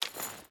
Footsteps / Water
Water Chain Run 2.ogg